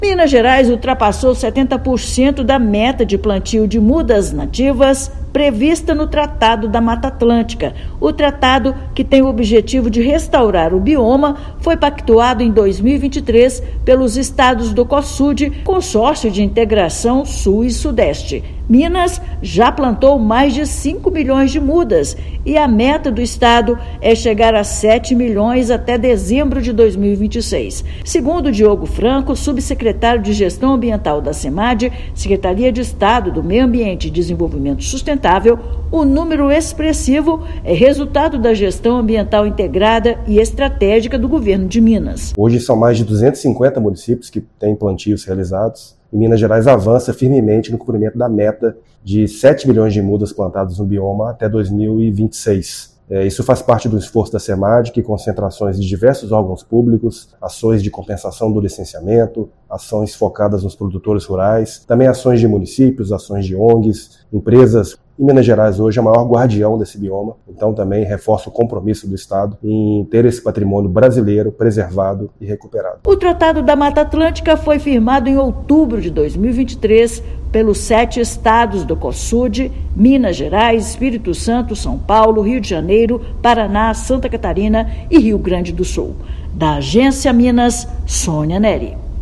[RÁDIO] Minas avança no reflorestamento e ultrapassa 70% da meta de restauração da Mata Atlântica
São mais de 5 milhões de mudas, consolidando o protagonismo do estado na agenda ambiental nacional. Ouça matéria de rádio.